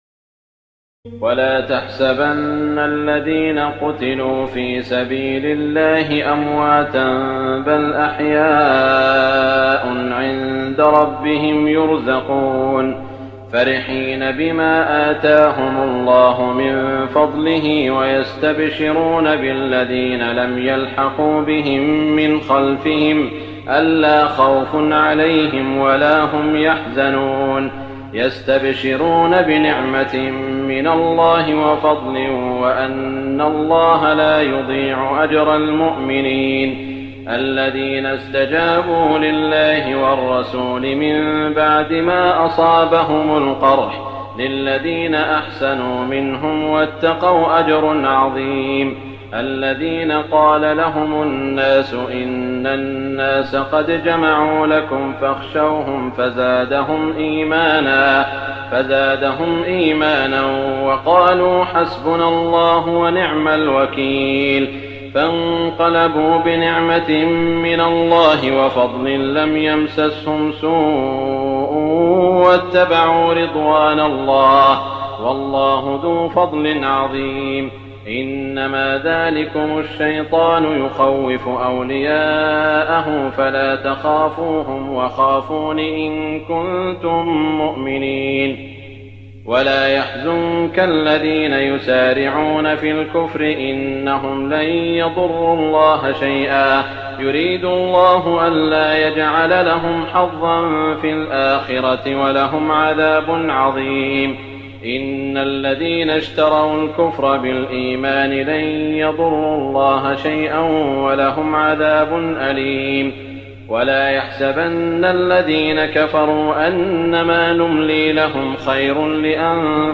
تراويح الليلة الرابعة رمضان 1419هـ من سورتي آل عمران (169-200) و النساء (1-35) Taraweeh 4st night Ramadan 1419H from Surah Aal-i-Imraan and An-Nisaa > تراويح الحرم المكي عام 1419 🕋 > التراويح - تلاوات الحرمين